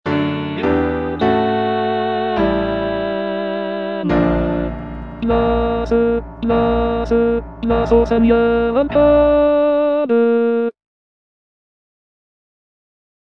G. BIZET - CHOIRS FROM "CARMEN" Place! Place! - Bass (Emphasised voice and other voices) Ads stop: auto-stop